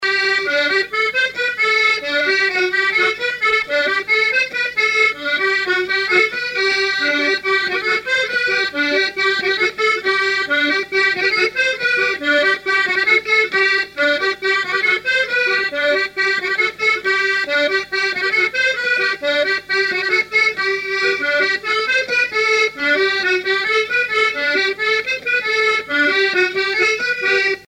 danse : branle : avant-deux
musique à danser à l'accordéon diatonique
Pièce musicale inédite